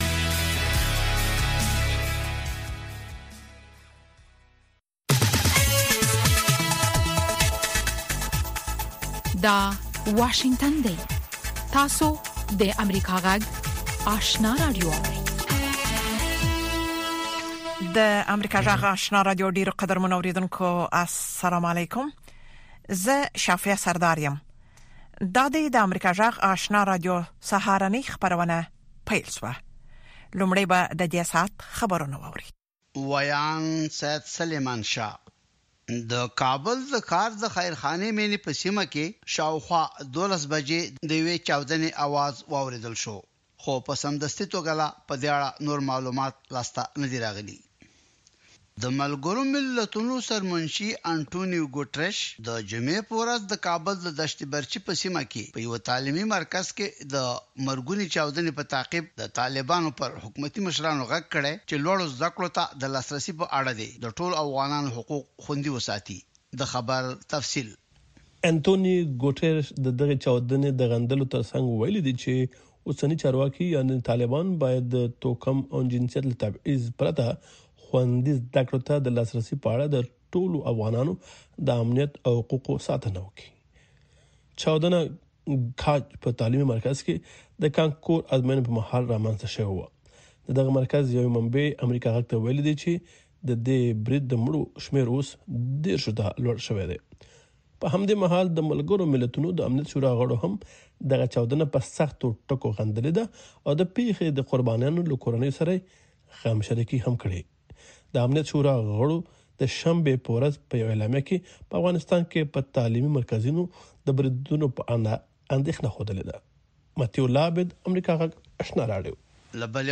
سهارنۍ خبري خپرونه